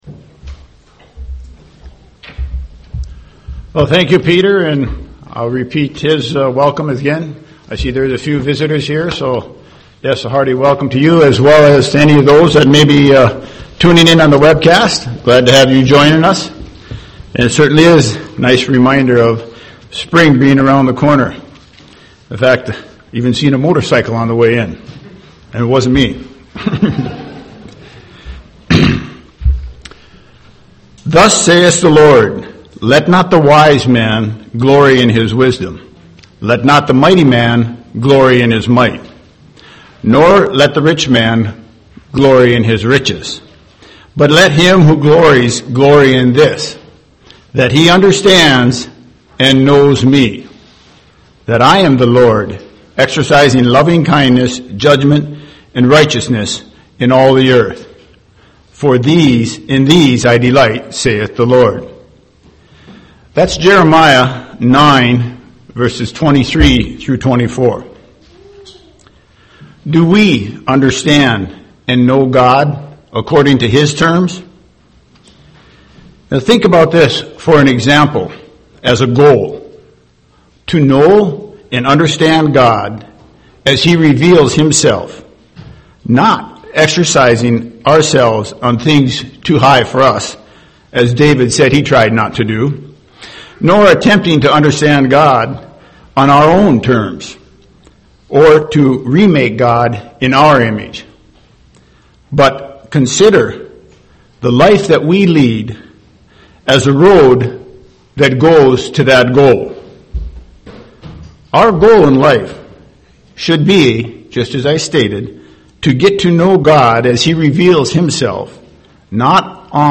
Given in Twin Cities, MN
UCG Sermon Studying the bible?